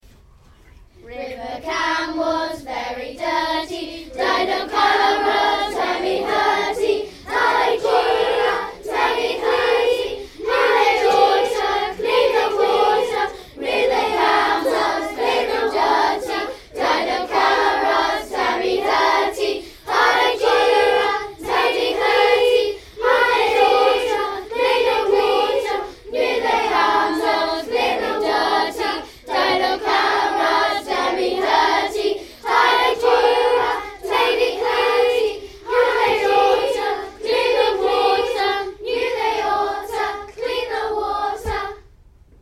This is a simple song which can be sung as rounds